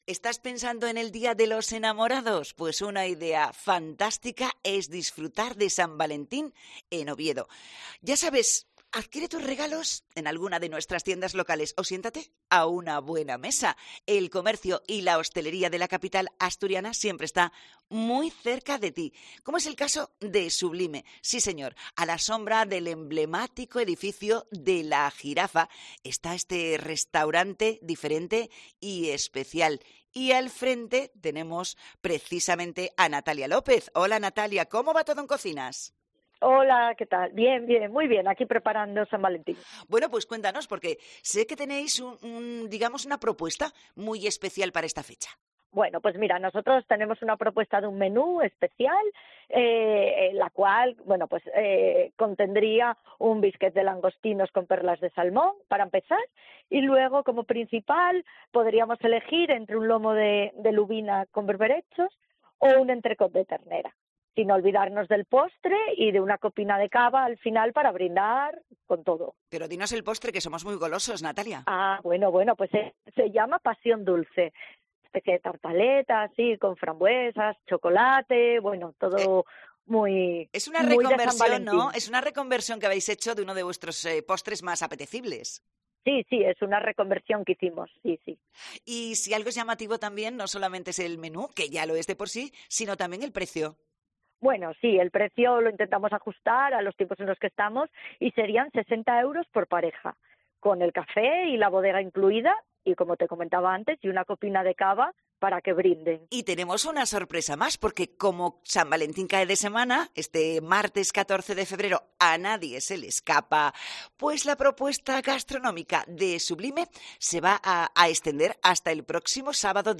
Oviedo enamora: celebra San Valentín en Sublime